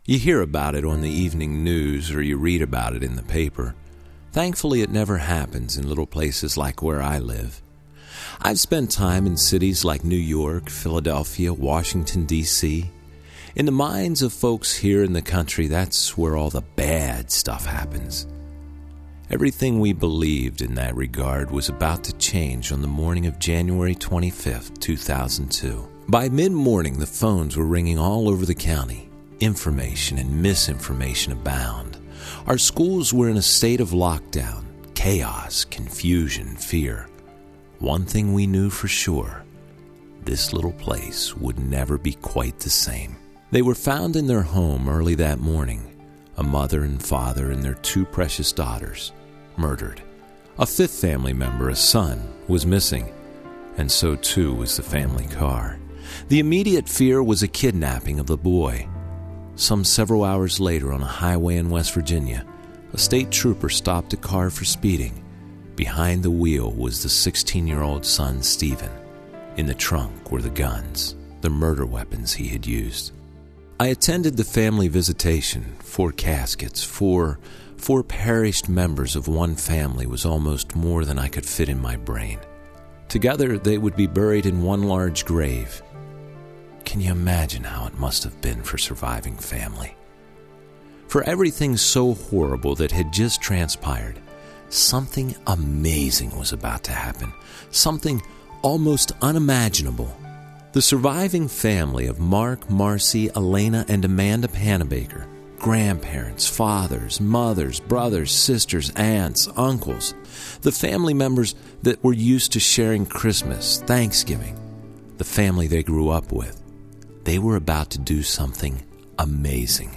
The History of The Wings Of Kindness Foundation Told by The American Storyteller